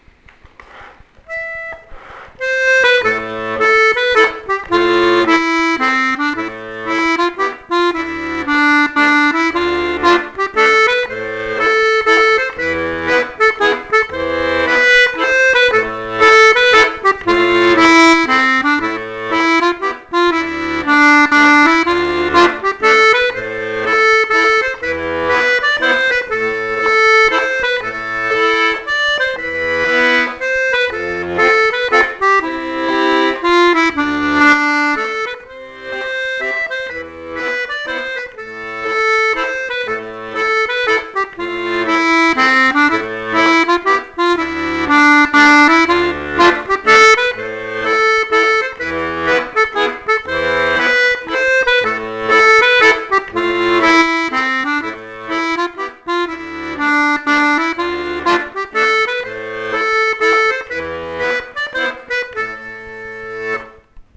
l'atelier d'accordéon diatonique
mazurka basque.mp3